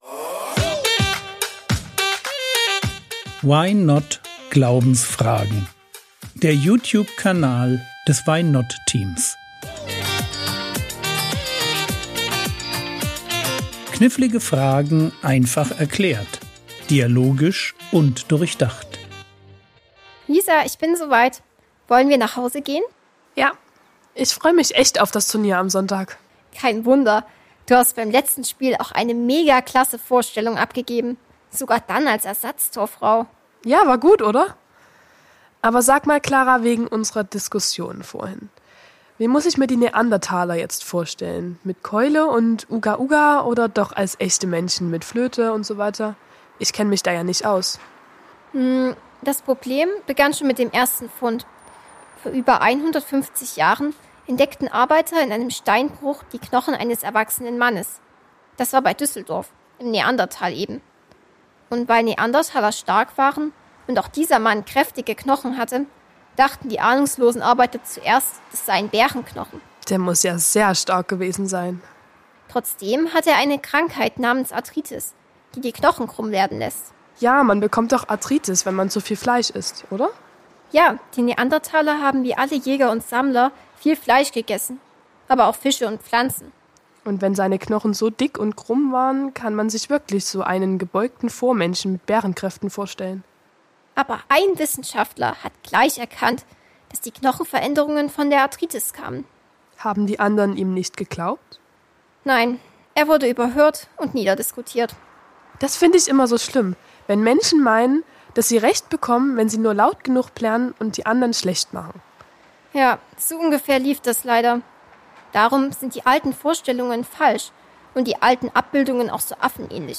Neandertaler ~ Frogwords Mini-Predigt Podcast